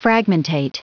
Prononciation du mot fragmentate en anglais (fichier audio)
Prononciation du mot : fragmentate